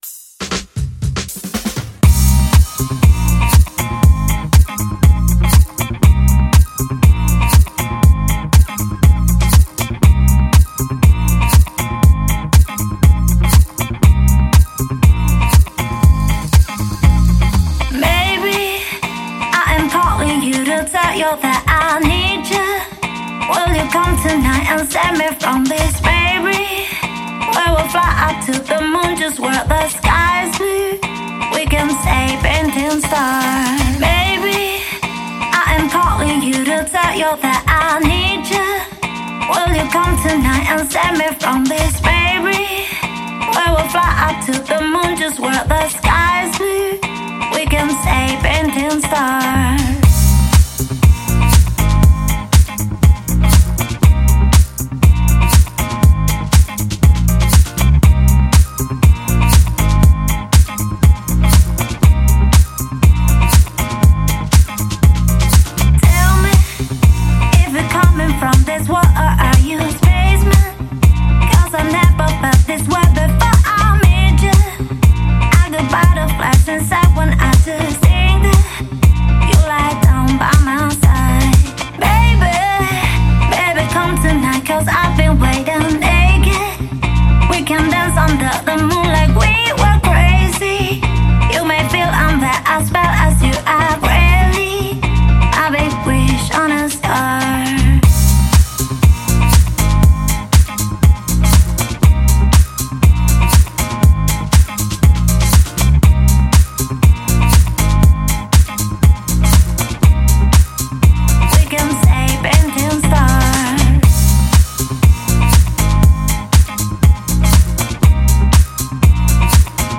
Žánr: Electro/Dance
Dalsi hit s prvkami house, zenskym vokalom a saxafonom.